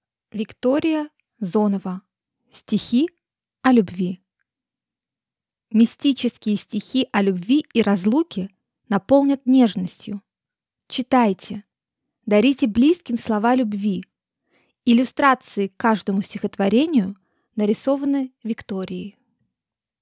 Аудиокнига Стихи о любви